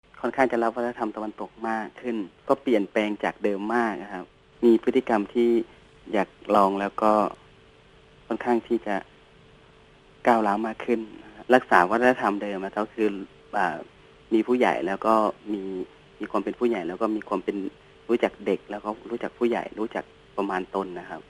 ເຈົ້າໜ້າທີ່ ກະຊວງຖແຫລງຂ່າວ ແລະວັດທະນະທໍາ ໃນນະຄອນຫລວງວຽງຈັນ ໃຫ້ຄວາມຄິດເຫັນ ກ່ຽວກັບເລື່ອງນີ້ວ່າ: